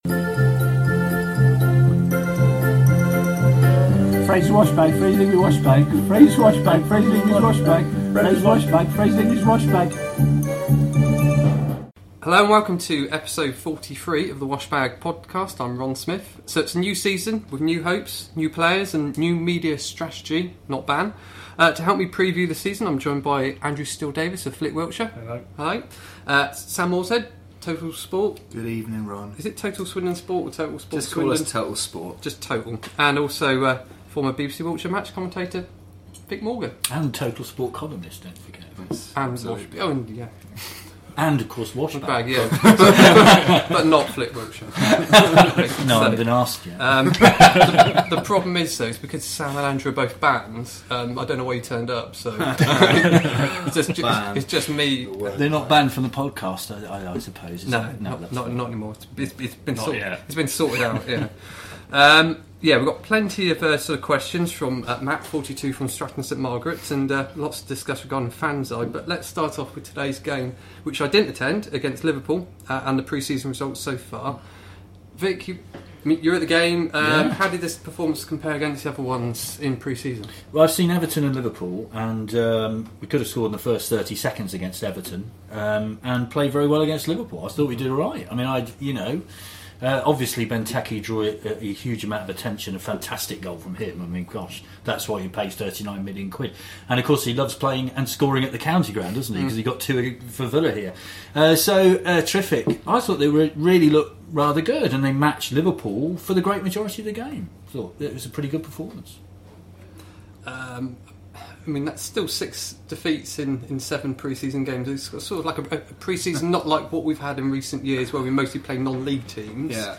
With the start of the 2015/16 season only days away the four are at the Roaring Donkey to discuss: